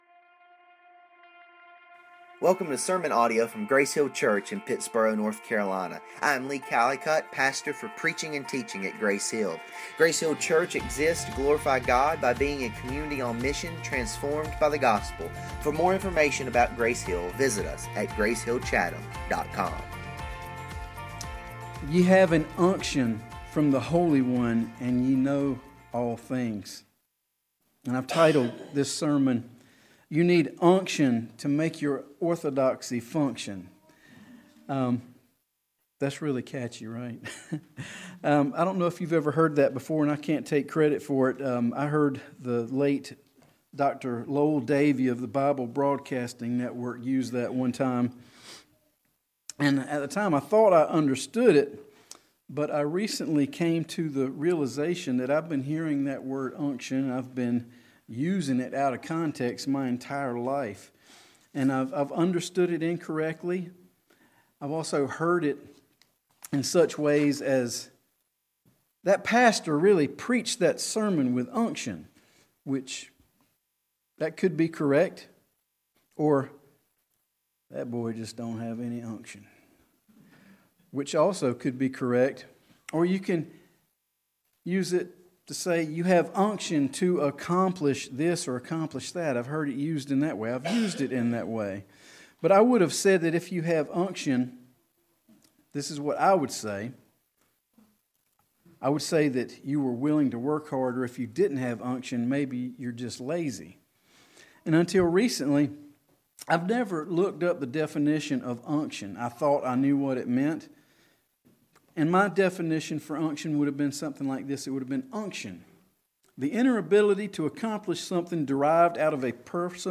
In this sermon we explore the vital role of the Holy Spirit in helping us not only know sound doctrine but live it out with discernment and perseverance.